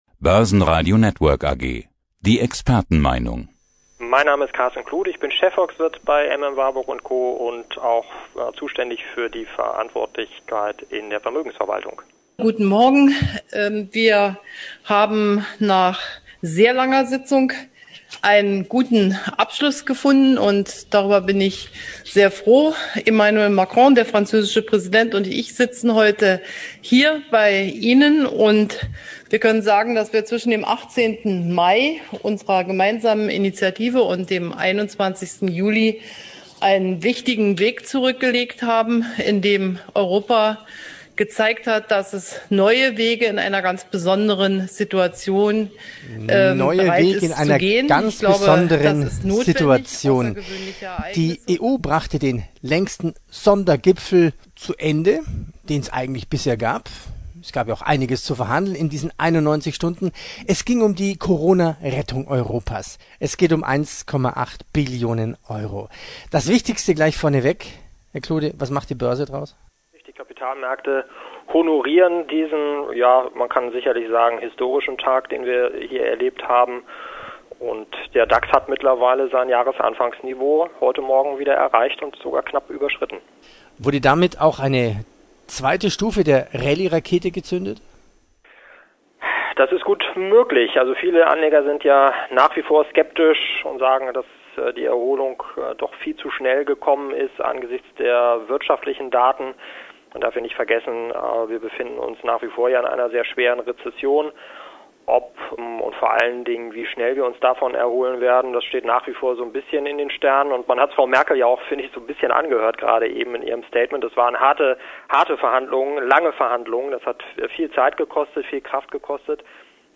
Downloads Zum Interview